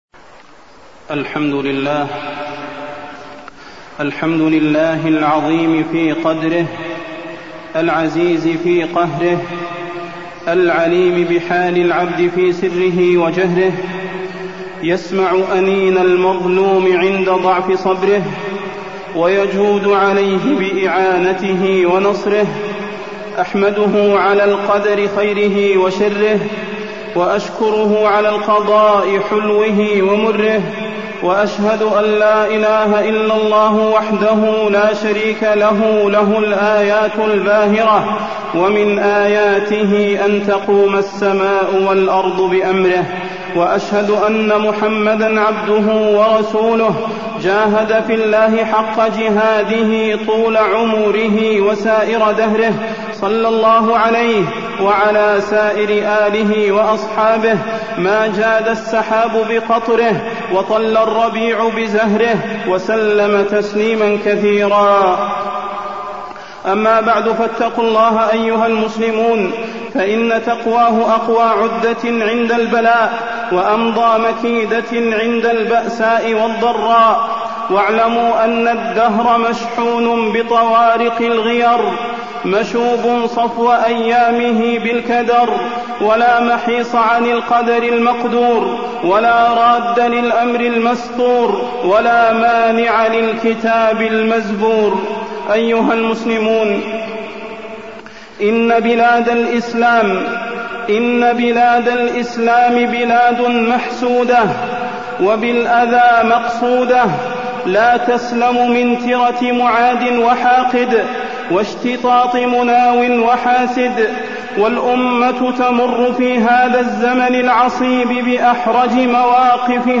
تاريخ النشر ٢ ربيع الثاني ١٤٢٥ هـ المكان: المسجد النبوي الشيخ: فضيلة الشيخ د. صلاح بن محمد البدير فضيلة الشيخ د. صلاح بن محمد البدير الأحداث الأخيرة